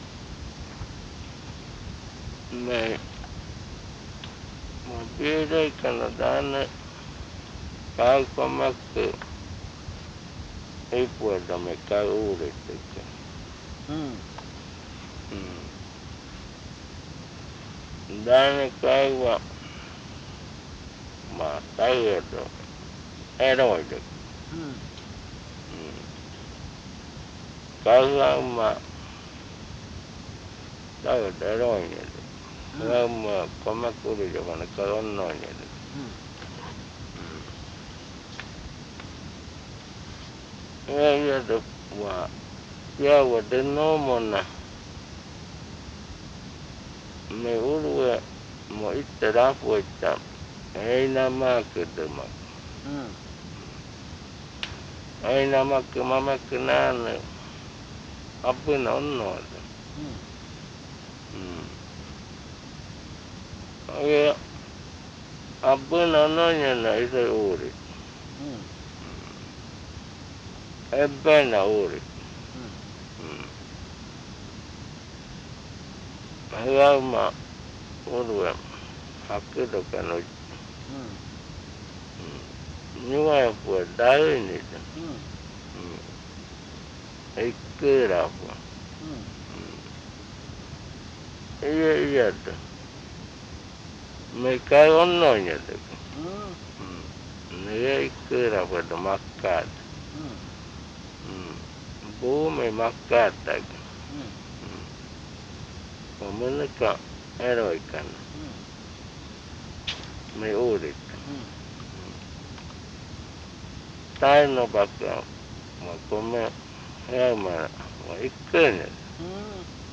Adofikɨ (Cordillera), río Igaraparaná, Amazonas